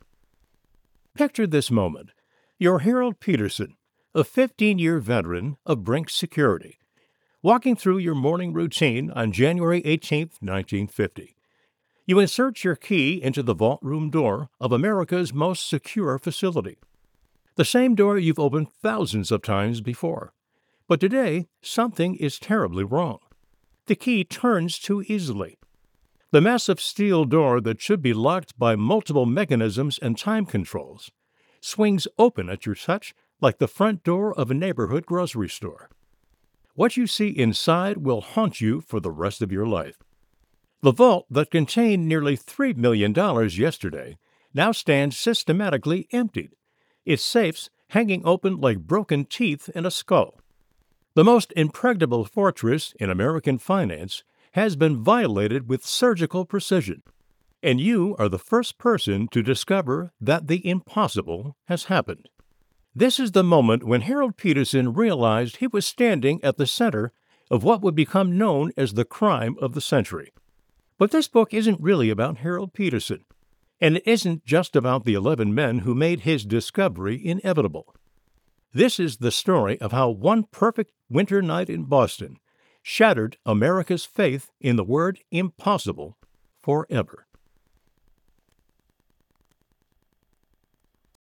home recording studio
Professional Voice Artist Genuine Personable Believable